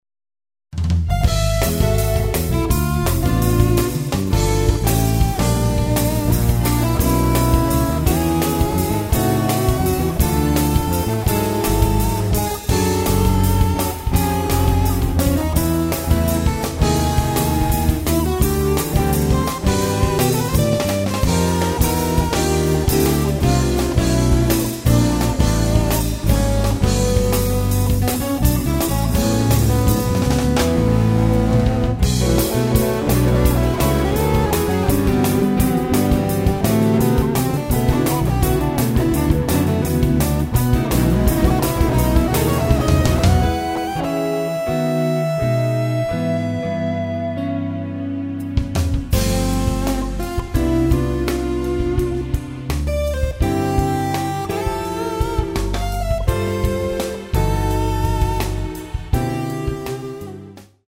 electric guitars & MIDI programming
drums
electric & acoustic bass
piano
keyboards
tenor saxophone